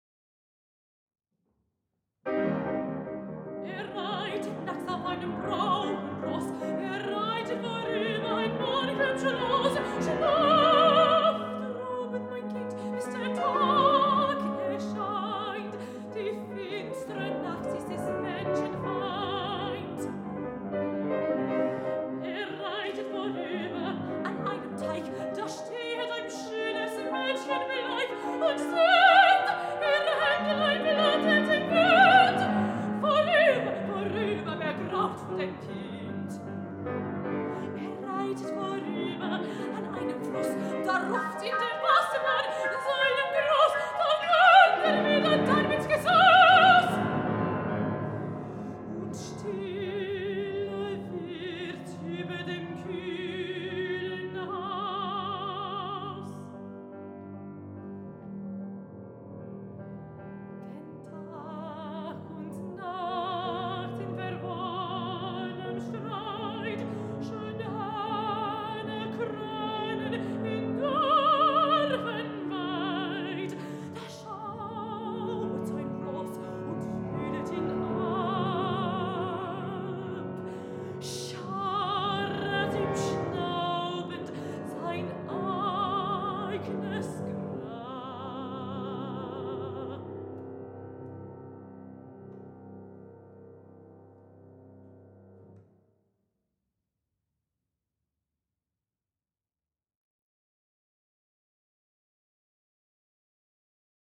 Piano Accompanist
mezzo-soprano
piano